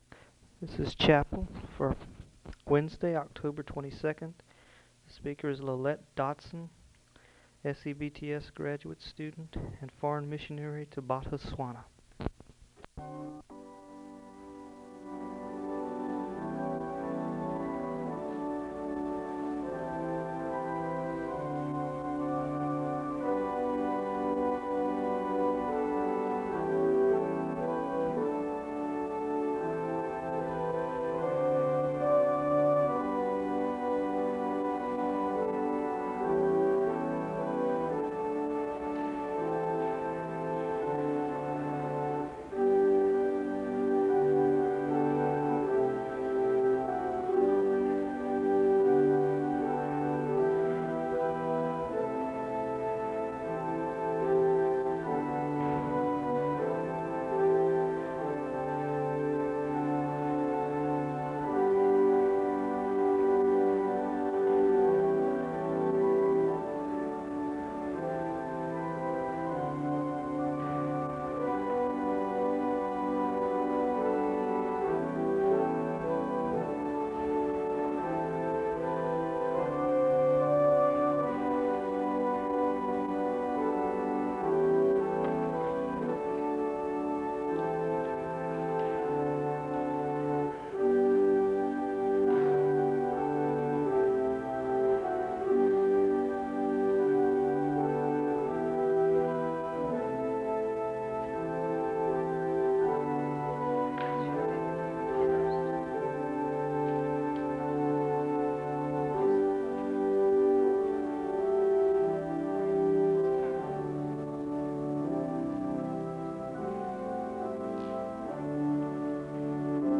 The service begins with organ music (0:00-3:38). There is a moment of prayer (3:39-4:30).
The choir sings a song of worship (6:11-9:09).
The service closes in a word of prayer (38:59-39:46).